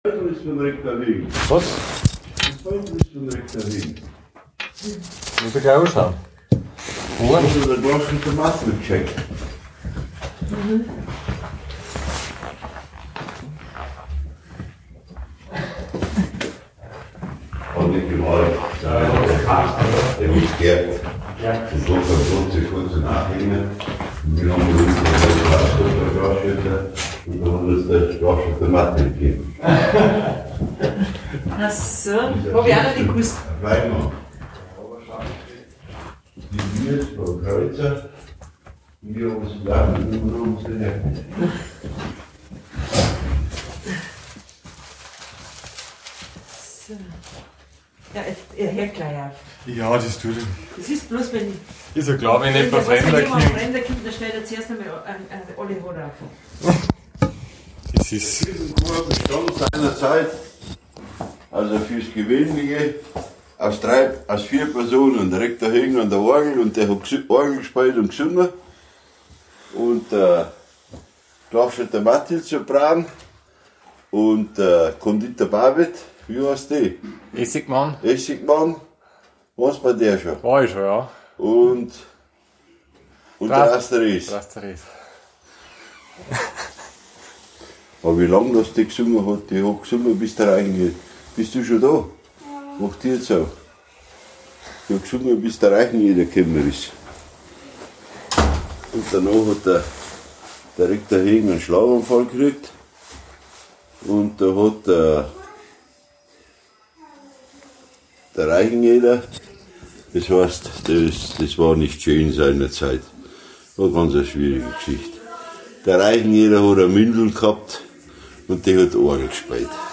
Download Audio-Datei: interview.m4a